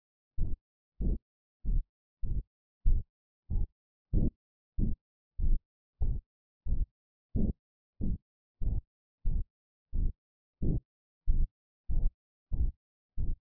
Coarctation of the Aorta
Systolic murmur, often with radiation to the back
Left infraclavicular area and back
Coarctation aorta.mp3